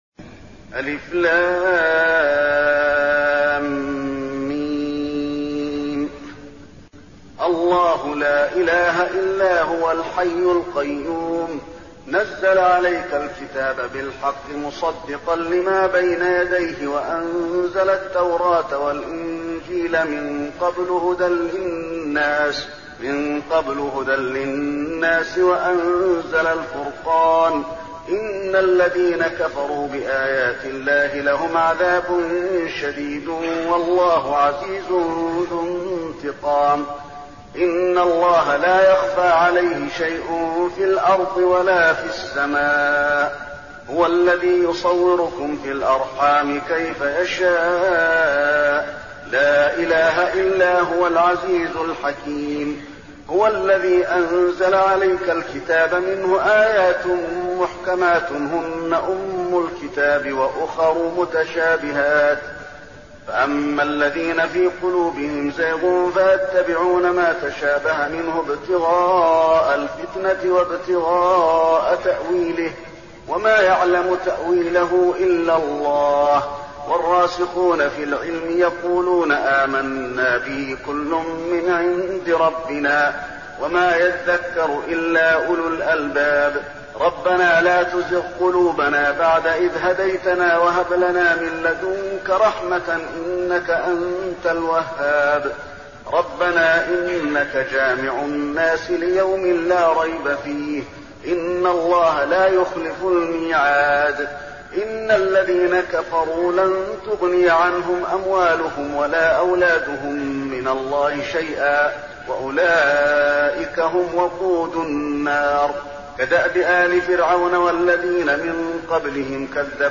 المكان: المسجد النبوي آل عمران